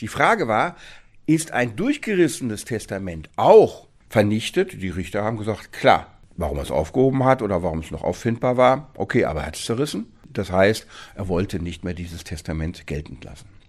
O-Ton: Durchgerissenes Testament steht gesetzlicher Erbfolge nicht entgegen – Vorabs Medienproduktion